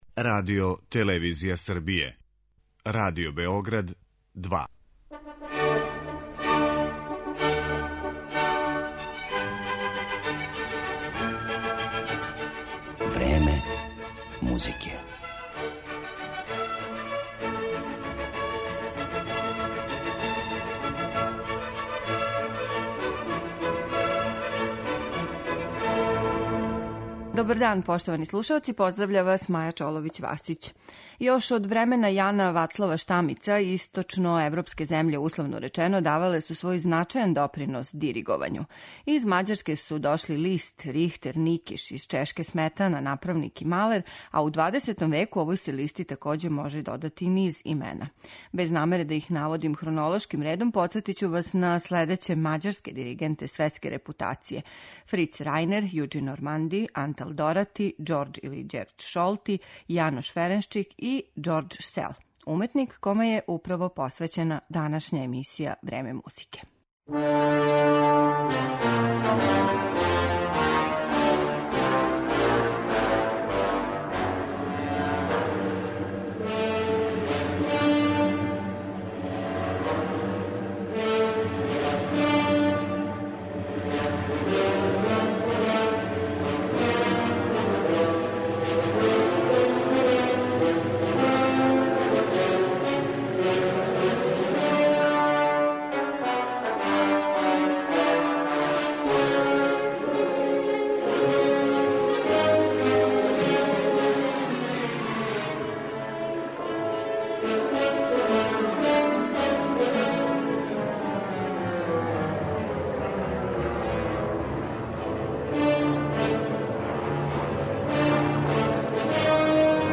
симфонијске музике